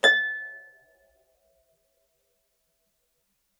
KSHarp_A6_mf.wav